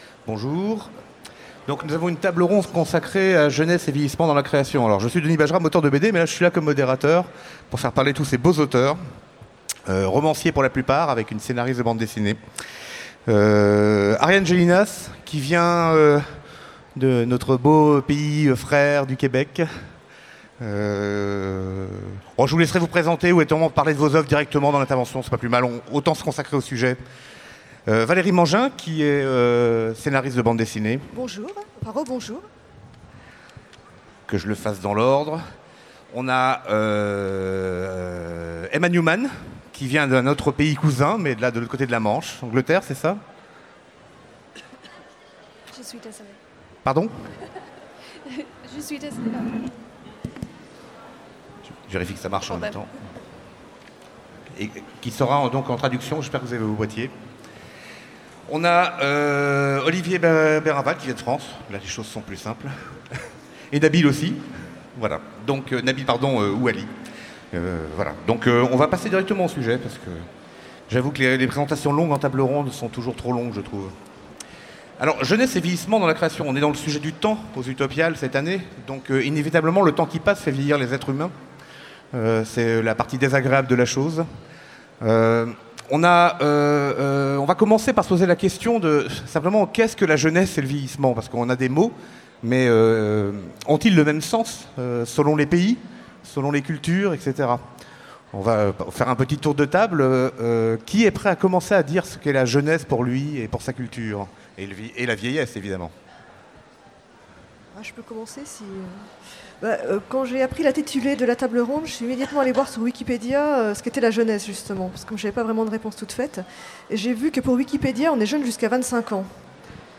Utopiales 2017 : Conférence Jeunesse et vieillissement dans la création